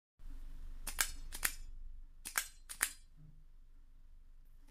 Listen to the confident LAMY 2000